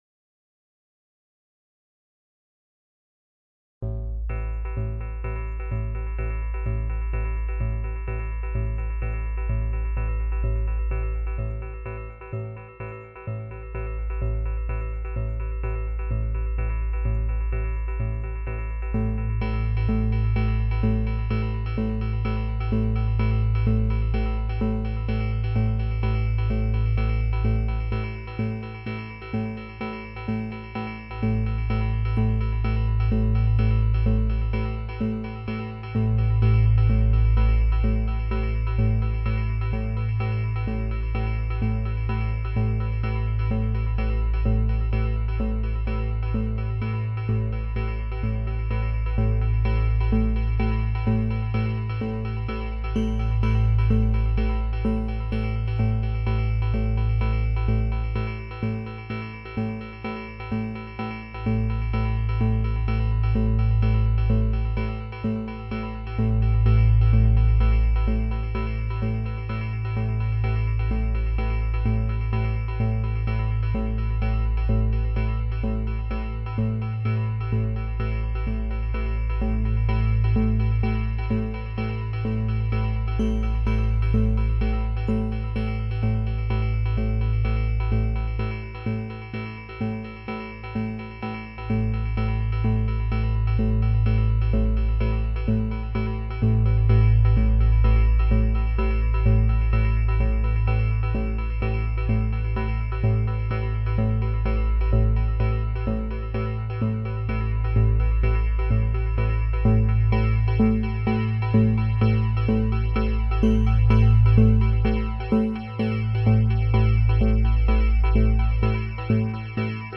Download Free Zebra Sound Effects
Download Zebra sound effect for free.